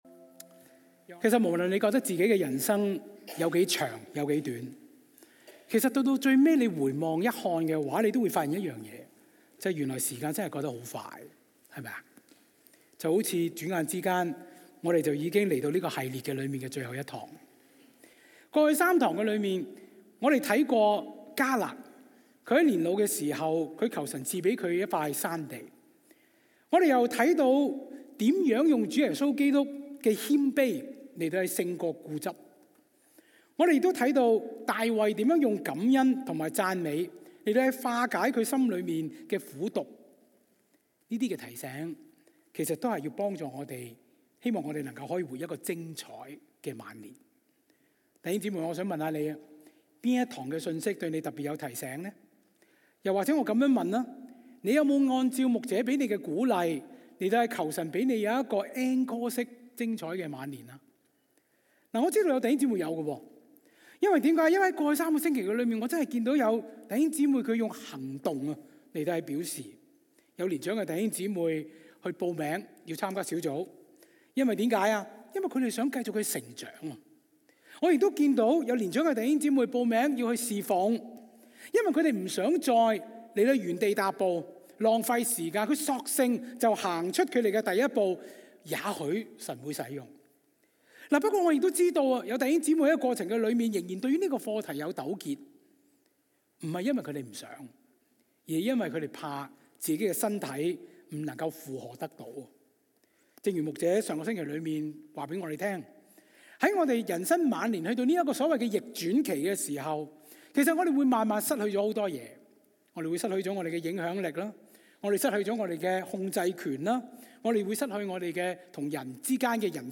經文
在這個講道系列「Encore」的最後一堂，講員帶領我們透過保羅在人生最後階段的剖白，學習如何將晚年的限制轉化為祝福，並為下一代留下美好的生命典範，活出一個完滿的 Encore 人生。